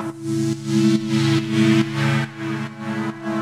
Index of /musicradar/sidechained-samples/140bpm
GnS_Pad-alesis1:4_140-A.wav